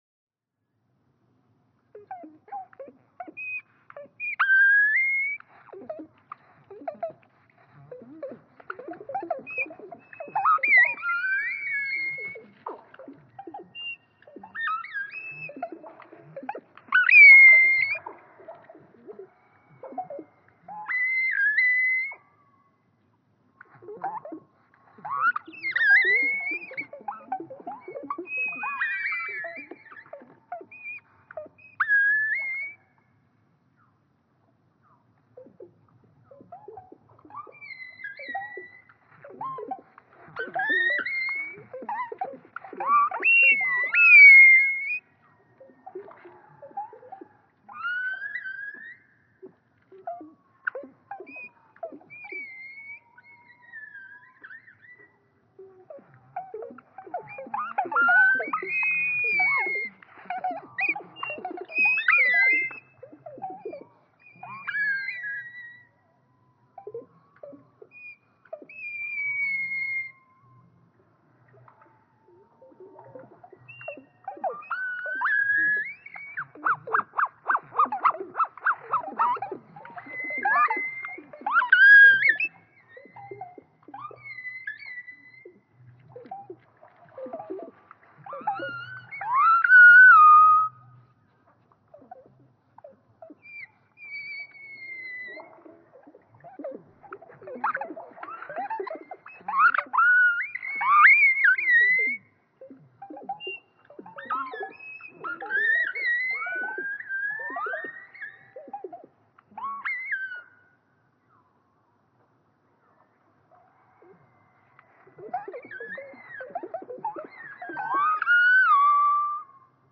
Brown-headed cowbird
Los Osos, California.
♫128. A selection of those same songs from the flock, slowed to quarter speed.
This detail is no doubt what the cowbirds can hear.
128_Brown-headed_Cowbird.mp3